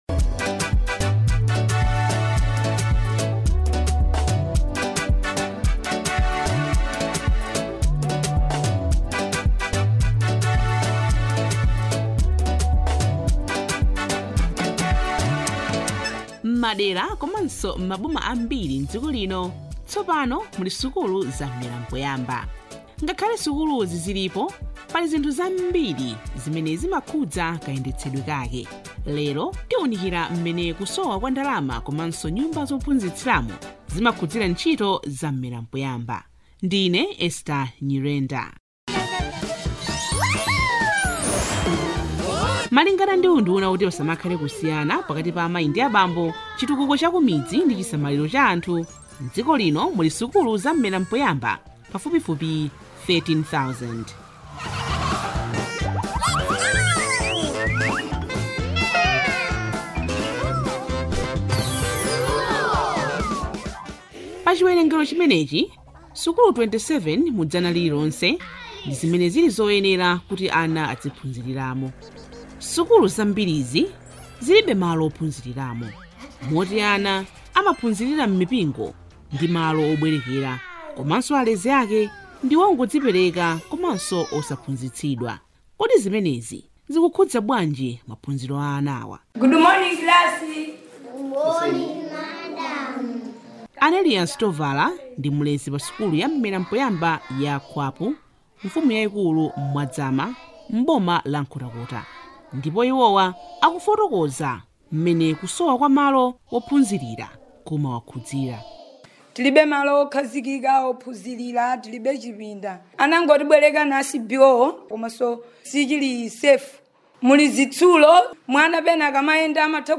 DOCUMENTARY ON ECONOMIC AND INFRASTRUCTURAL ON ECD - Part 2